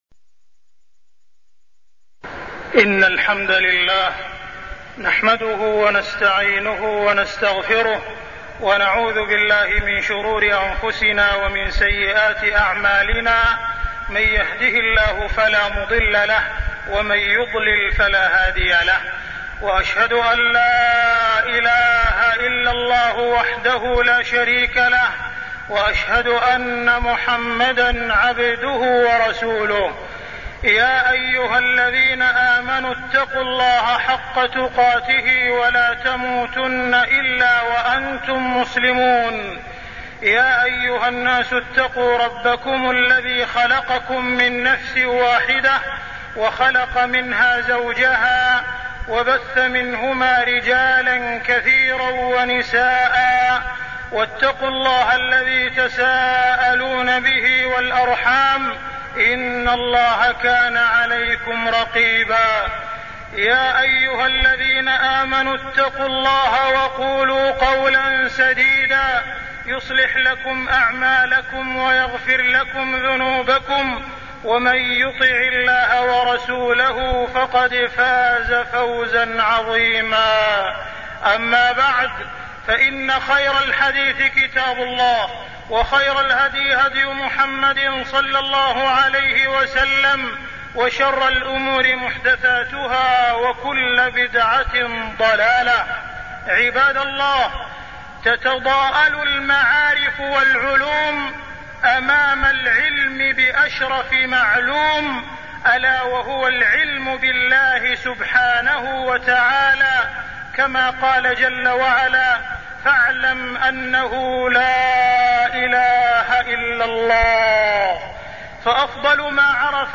تاريخ النشر ٢١ محرم ١٤٢٠ هـ المكان: المسجد الحرام الشيخ: معالي الشيخ أ.د. عبدالرحمن بن عبدالعزيز السديس معالي الشيخ أ.د. عبدالرحمن بن عبدالعزيز السديس أسماء الله وصفاته